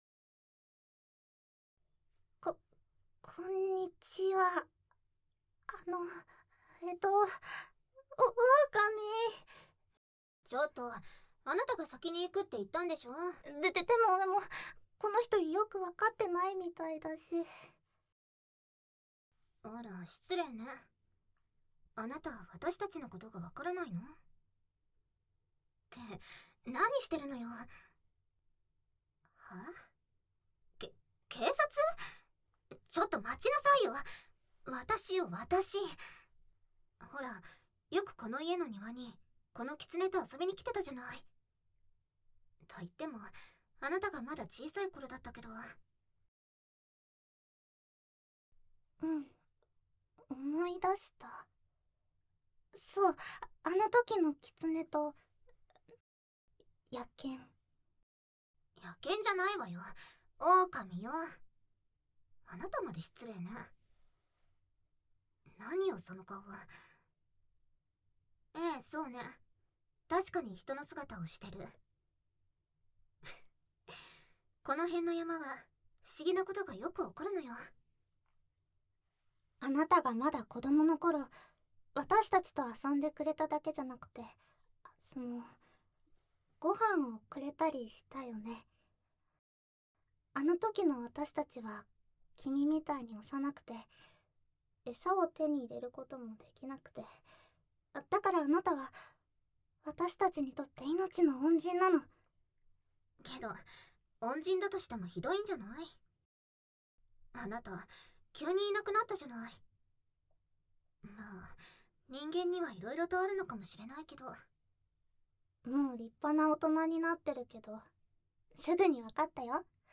掏耳 环绕音 ASMR 低语